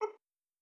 SFX / boop / B.wav